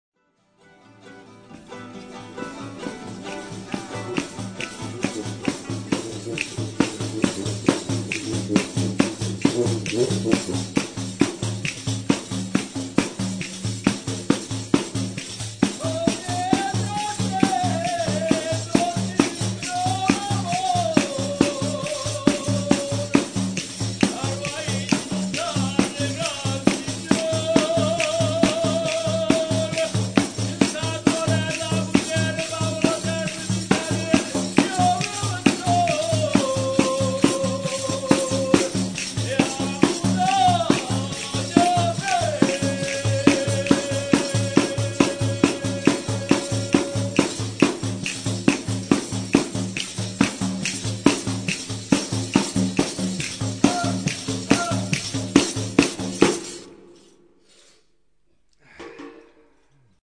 chitarra, chitarra battente, mandolino, mandola, percussioni
tammorra, putipù e scetavaisse